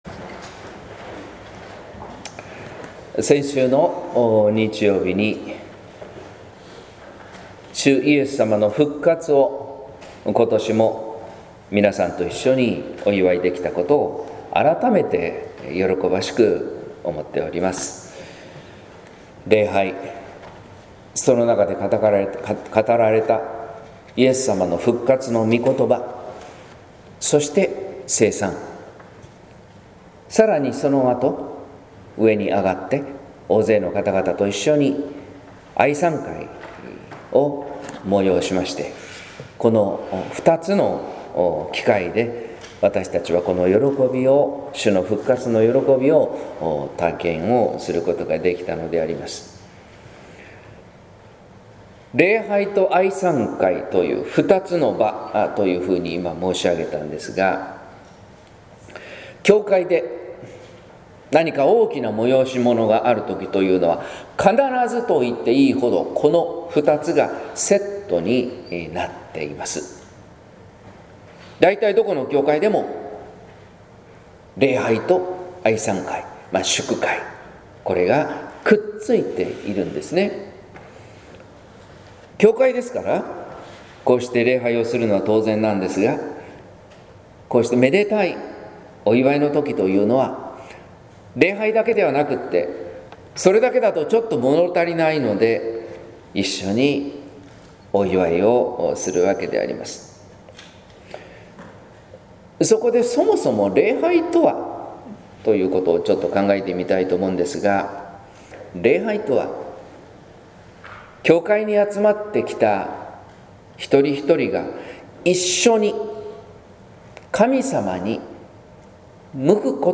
説教「末広がりの恵み」（音声版）